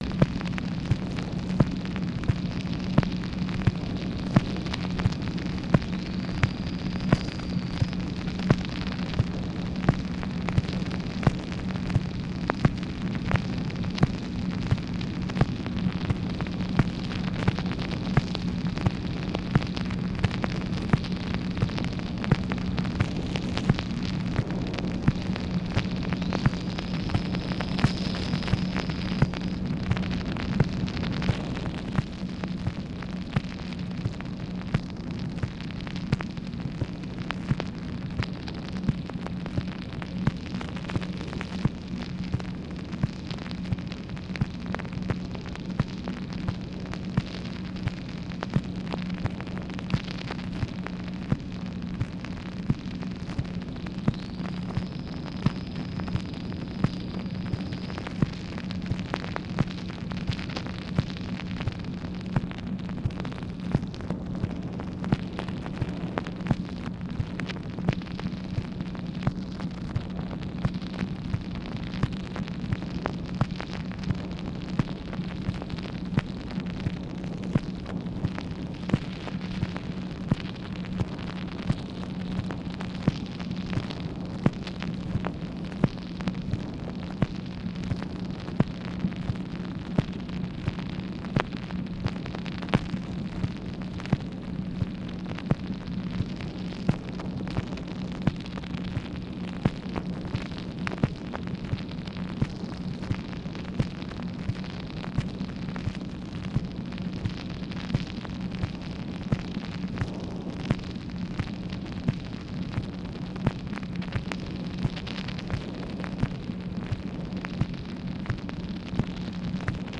MACHINE NOISE, June 21, 1965